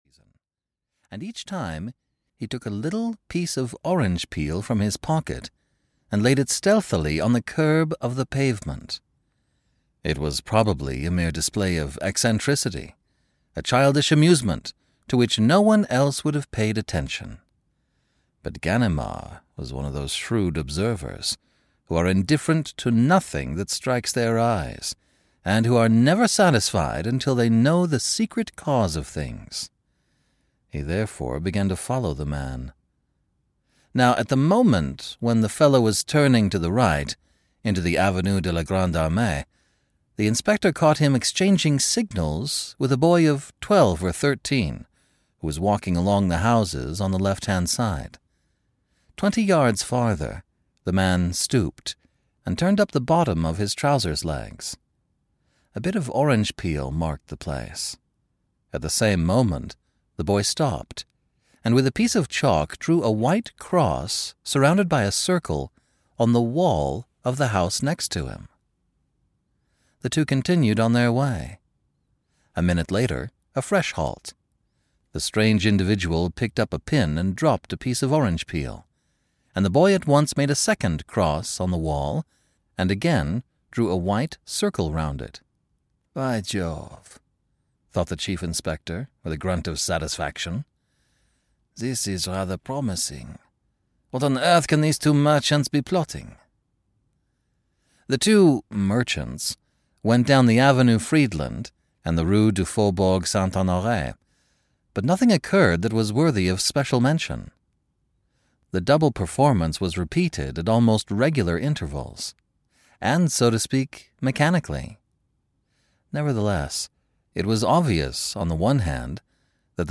Ukázka z knihy
He masterfully breathes life back into literary classics and plays with a wide array of voices and accents and has produced over 500 audiobooks.